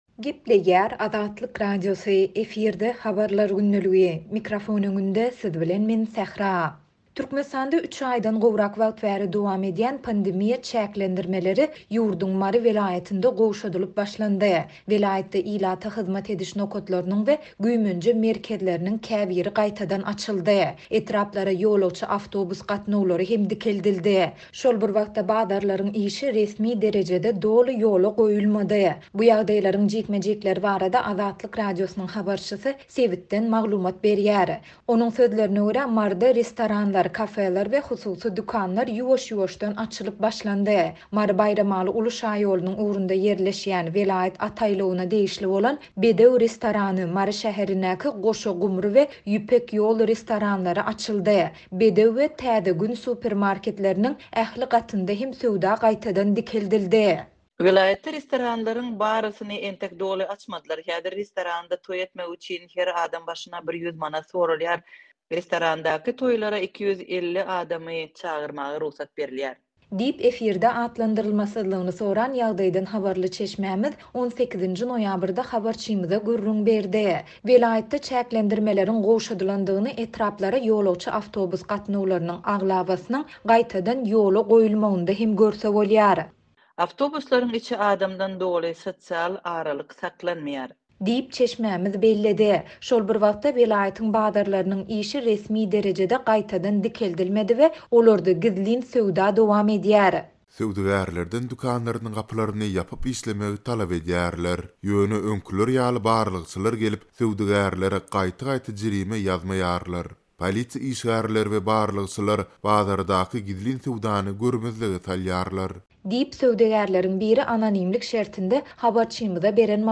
Bu ýagdaýlaryň jikme-jikleri barada Azatlyk Radiosynyň habarçysy sebitden habar berdi.